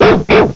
cry_not_furfrou.aif